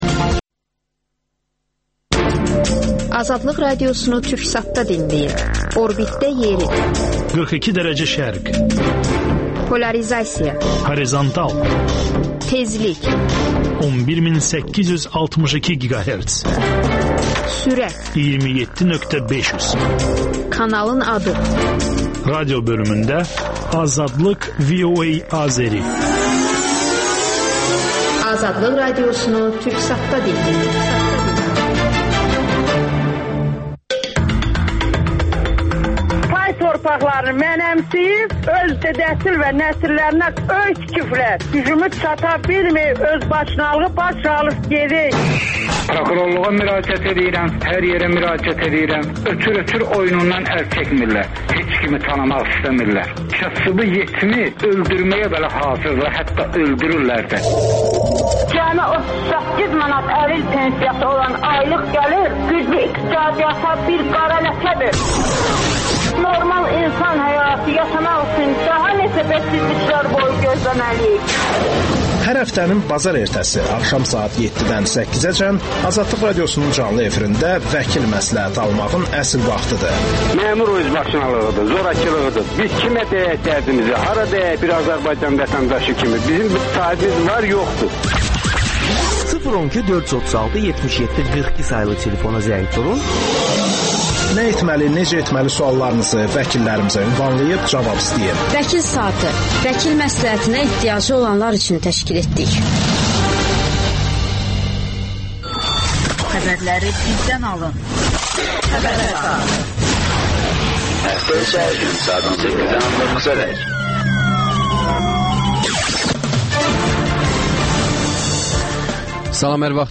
AzadlıqRadiosunun müxbirləri ölkə və dünyadakı bu və başqa olaylardan canlı efirdə söz açırlar. Günün sualı: Azərbaycanda hansı dövlət qurumu rüşvətsiz çalışır?